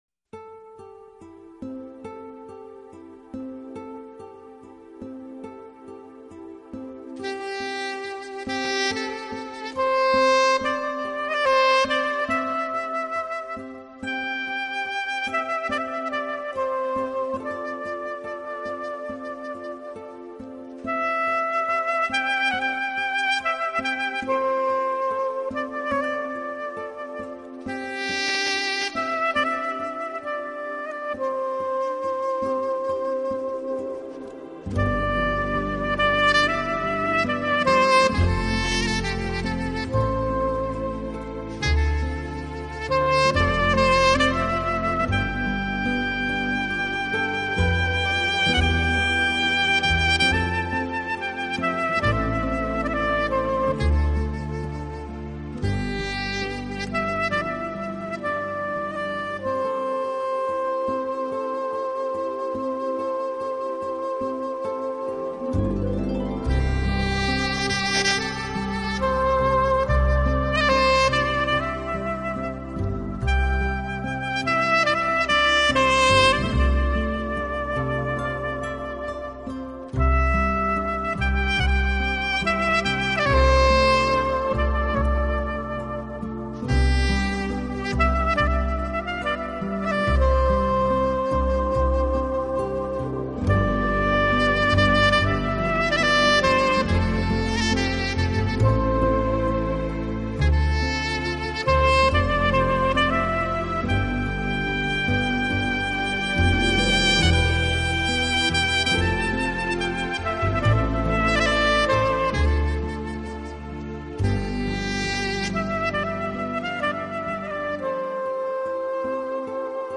纯音萨克斯
Acoustic（原音）是指原声乐器弹出的自然琴声（原音），制作录音绝对不含味精，乐
本套CD音乐之音源采用当今世界DVD音源制作最高标准：96Khz/24Bit取样录制，
这点又像铜管乐器，其音色独特，演奏即兴感强，成为流行音乐及爵士乐经常采用的乐器。